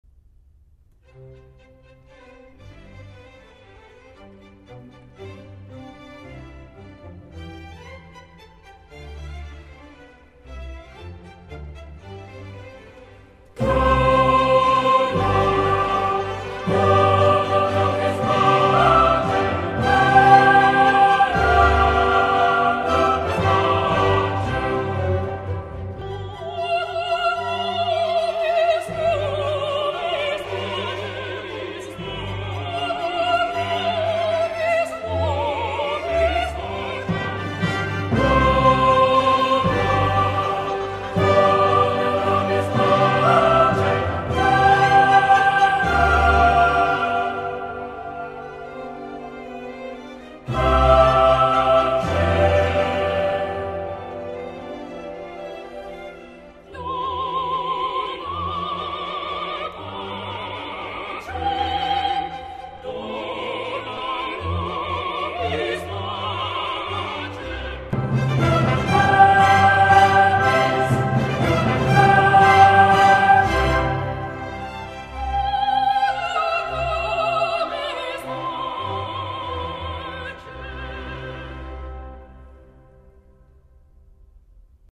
Género: Classical.